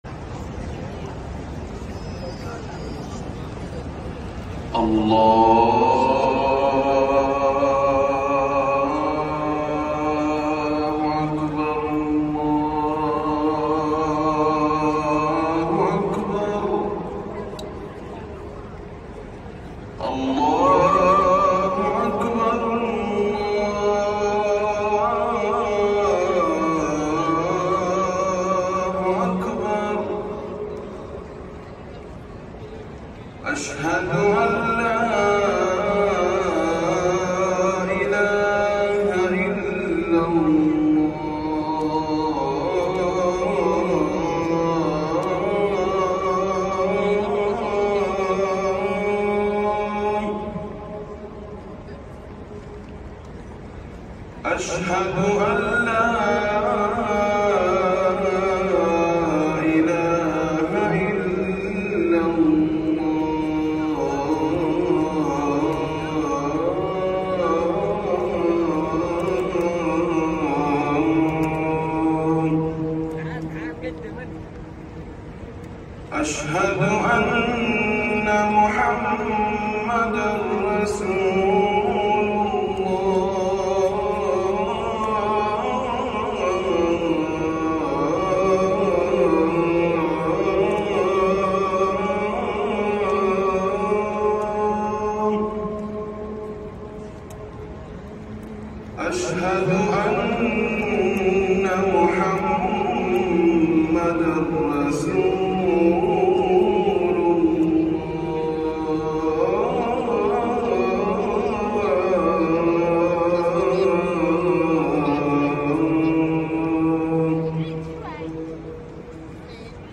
أذان الفجر الأول
الأذان الأول لصلاة الفجر